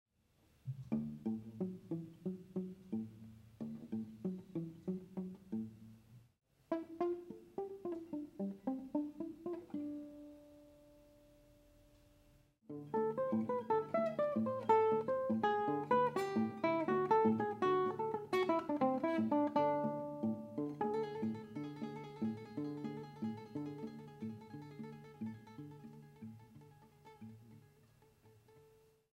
108. Pizzicati - três exemplos.m4v